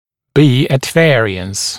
[biː ət ‘veərɪəns][би: эт ‘вэариэнс]отличаться, расходиться